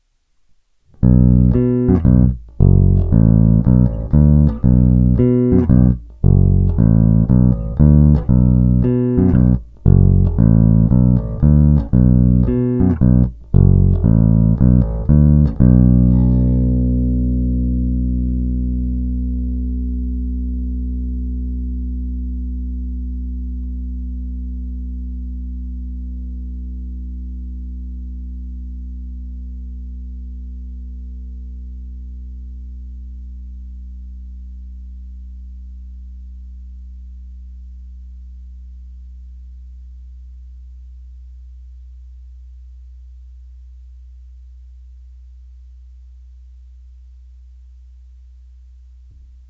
Zvuk má očekávatelně moderní charakter, je pevný, konkrétní, vrčí, má ty správné středy důležité pro prosazení se v kapele.
Není-li uvedeno jinak, následující nahrávky jsou provedeny rovnou do zvukové karty a s korekcemi na středu a dále jen normalizovány, tedy ponechány bez postprocesingových úprav.
Snímač u krku